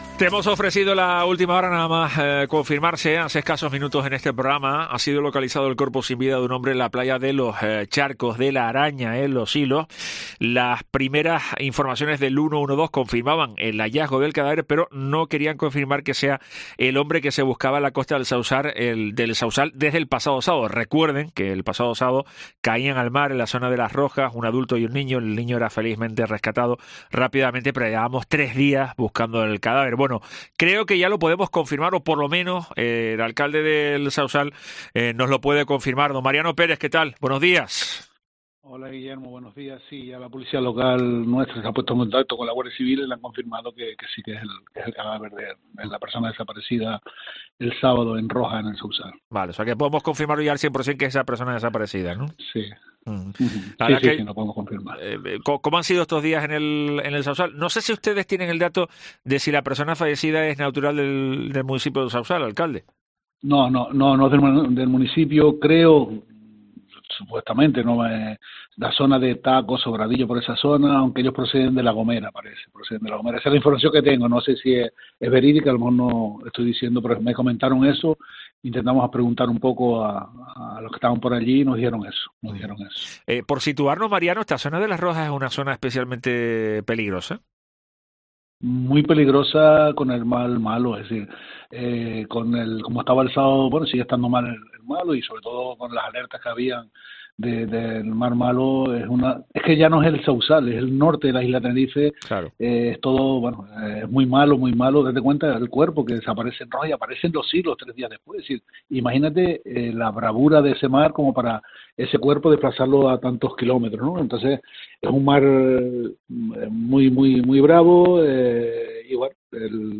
El alcalde de El Sauzal, Mariano Pérez, ha confirmado en Herrera en COPE Tenerife la identidad del varón, que llevaba desaparecido cuatro días.